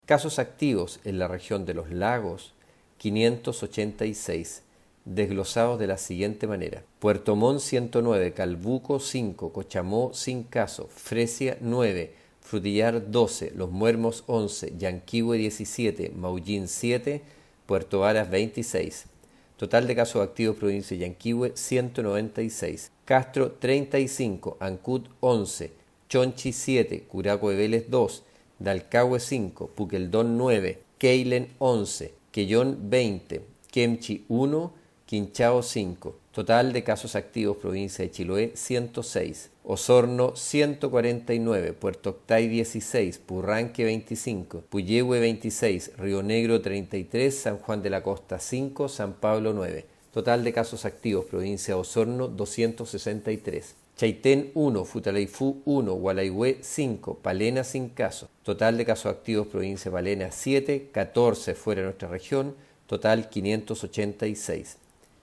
La autoridad de salud informó, además, acerca de los casos activos en la región: